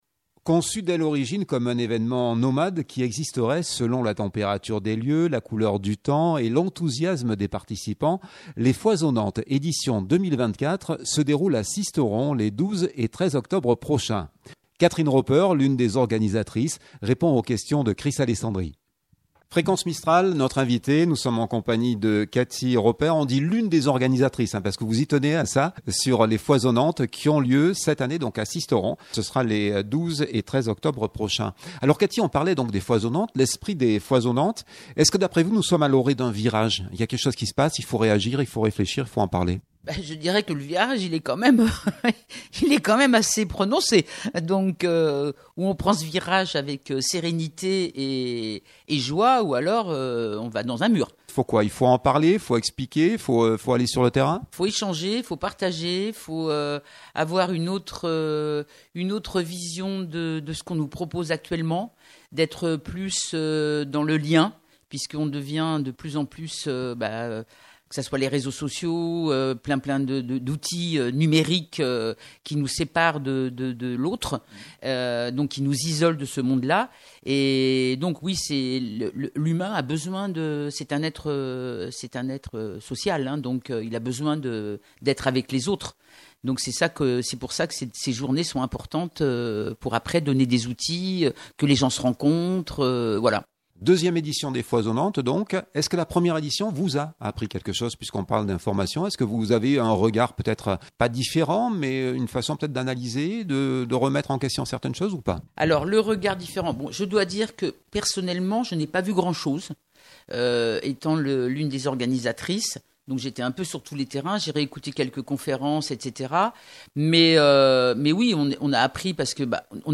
2024-09-26 - Sisteron-Les Foisonnates-reportage.mp3 (11.86 Mo)